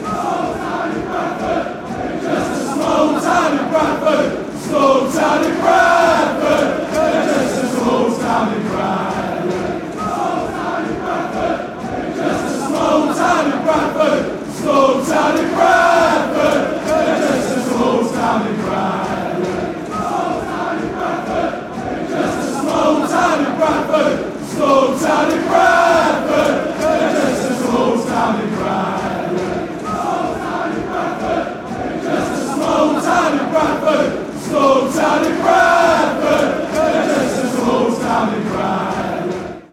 A WFC soccer chant.